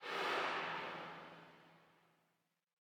ambienturban_15.ogg